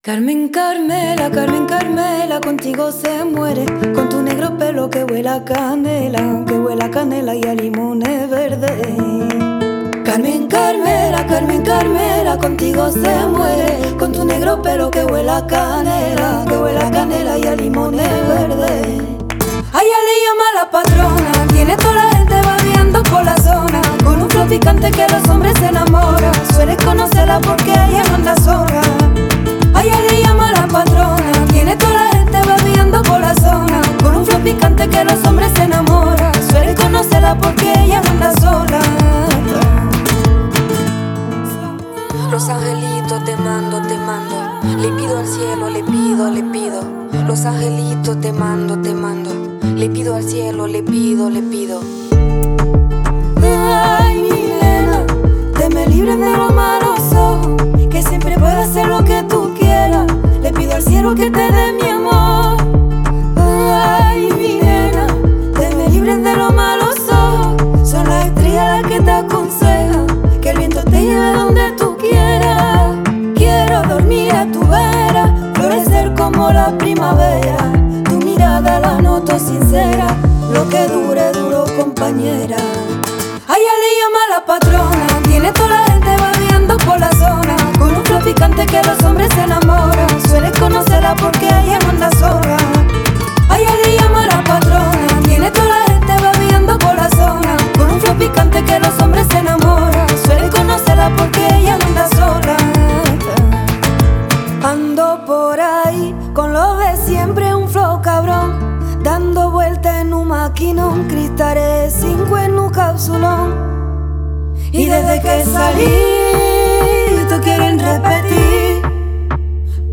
mêlant tradition flamenco et modernité.